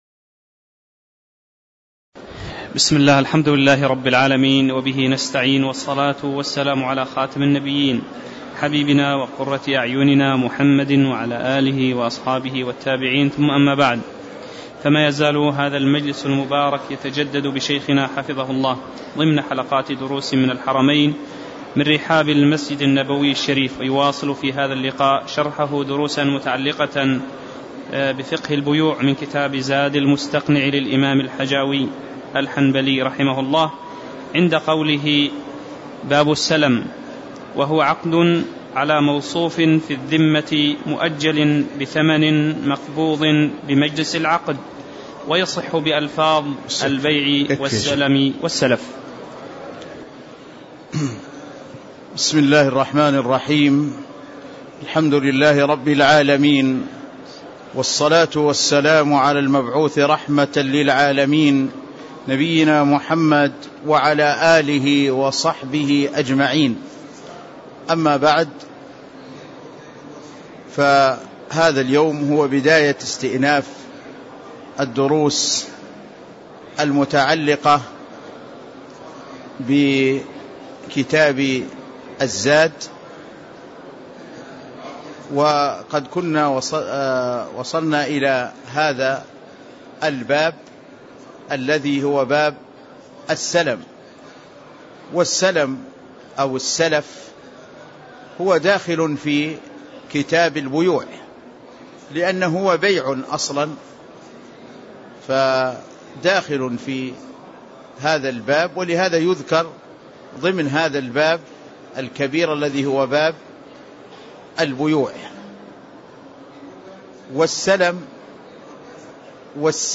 تاريخ النشر ٥ محرم ١٤٣٧ هـ المكان: المسجد النبوي الشيخ